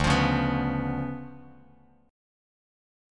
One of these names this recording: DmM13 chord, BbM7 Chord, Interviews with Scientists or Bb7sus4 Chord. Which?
DmM13 chord